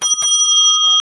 ding_2.wav